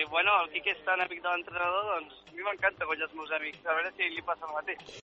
durant l’entrevista que va concedir al programa d’esports “La Banqueta” d’aquesta casa